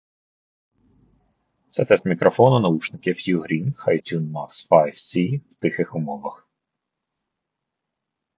Мікрофон
Мікрофон в UGREEN HiTune Max5c непоганий, на 7 з 10, зроблений на достатньому рівні як по класу, так і в цілому.
В тихих умовах: